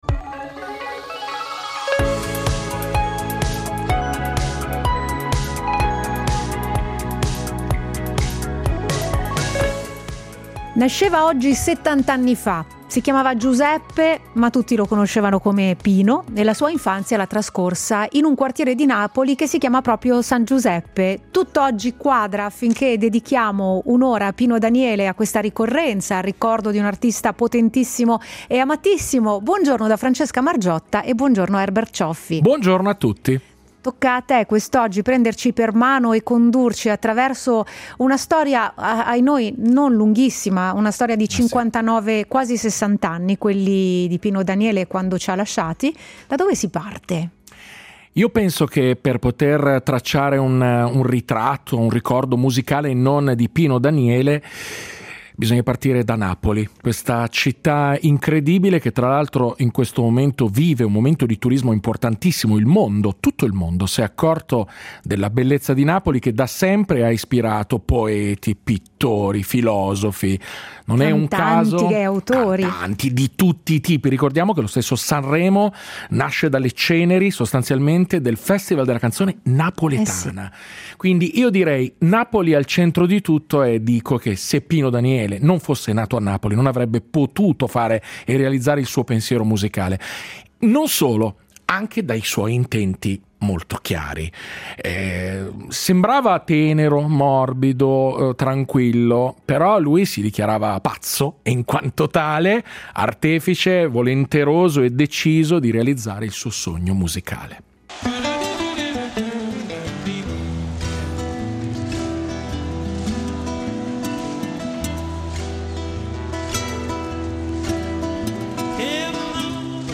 Lo speciale del programma radiofonico Millevoci della RSI: